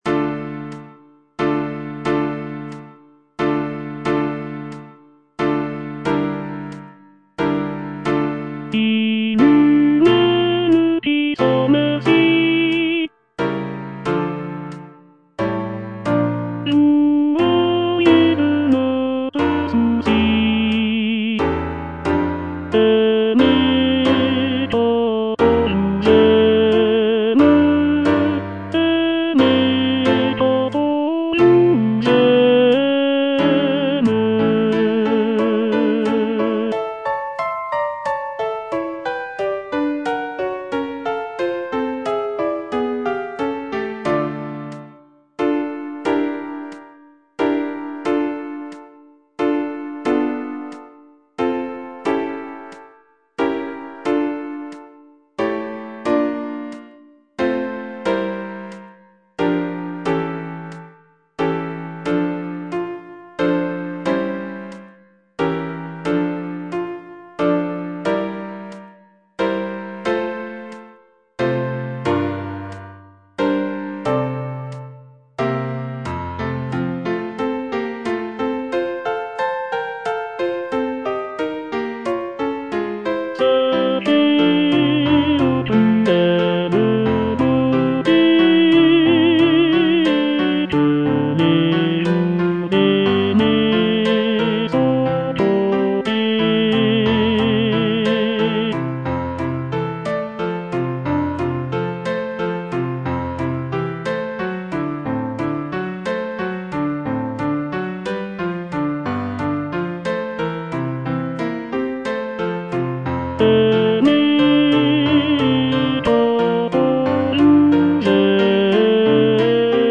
G. FAURÉ - MADRIGAL Tenor (Voice with metronome) Ads stop: auto-stop
It is a setting of a French poem by Armand Silvestre, featuring lush harmonies and intricate counterpoint typical of Fauré's style. The piece is written for four-part mixed choir and piano accompaniment, and showcases Fauré's skill in crafting elegant, lyrical melodies.